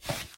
SkipWalk5.ogg